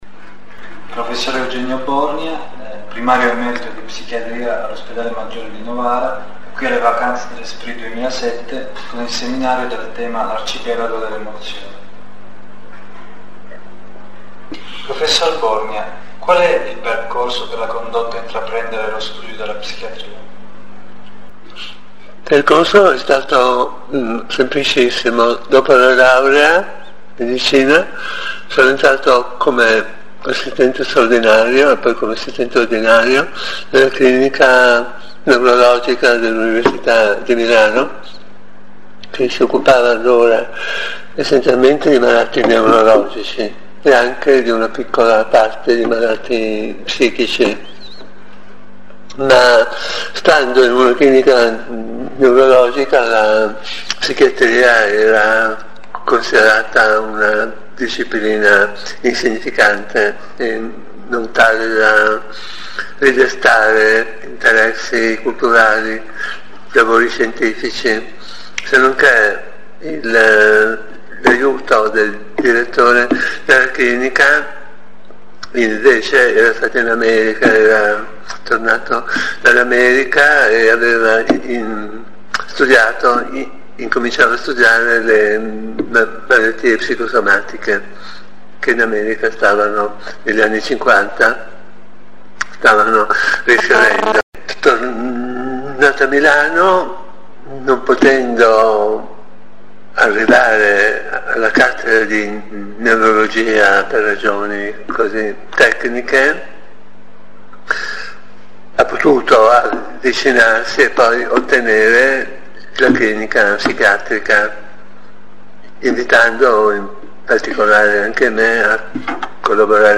Audio intervista a Eugenio Borgna